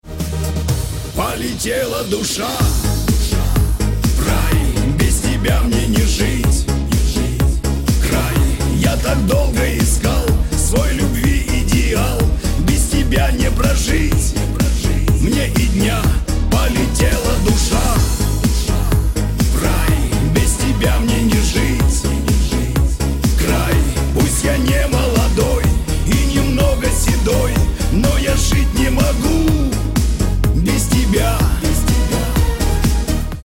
• Качество: 128, Stereo
мужской вокал
русский шансон